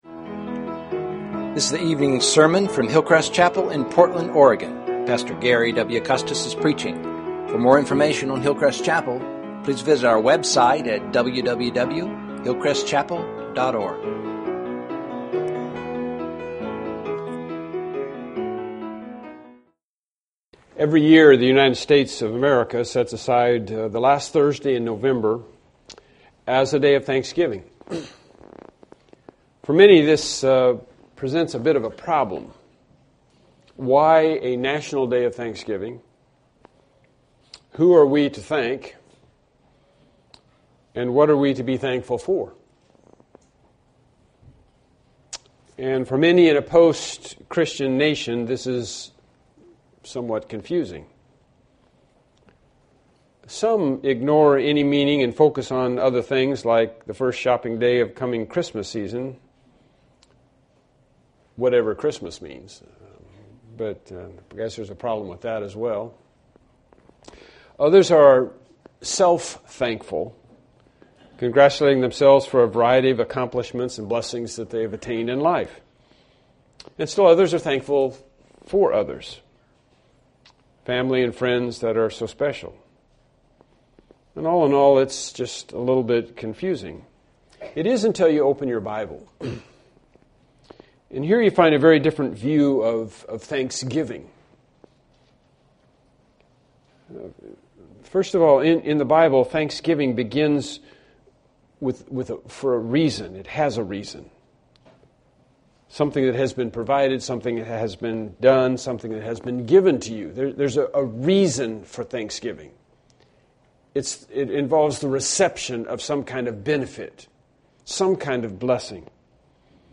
Series: Evening Sermons
Selected Verses Service Type: Evening Worship Service Topics